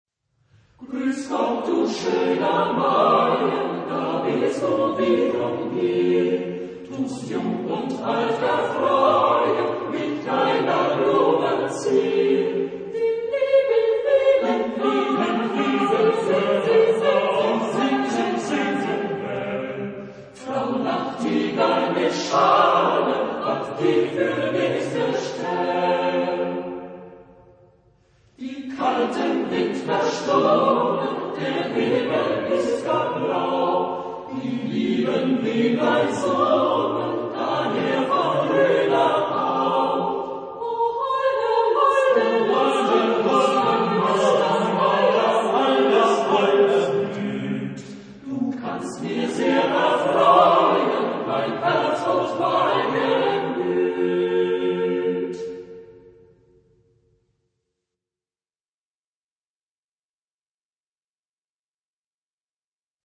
Genre-Style-Form: Folk music ; Partsong ; Secular
Type of Choir: SATB  (4 mixed voices )
Tonality: F major